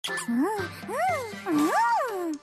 Nezuko happy noises